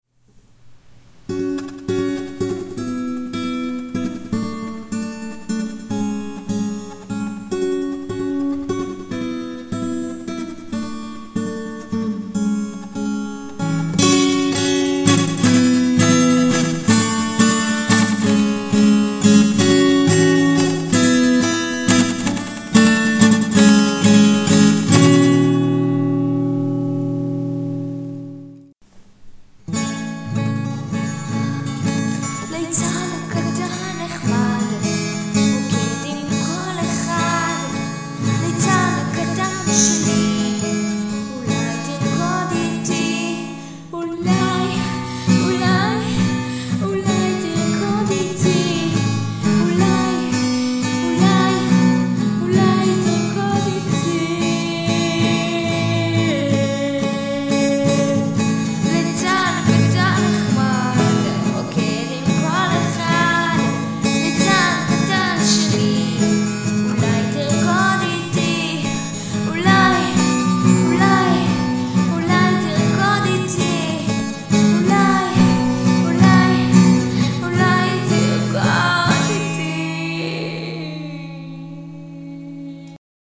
(חחחח...תנמיכו קצת כי ההמשך בטעות = צורח=..!
וגם הנגינה לא משהו..
נדמה לי שיש בעייה בקצב/פריטה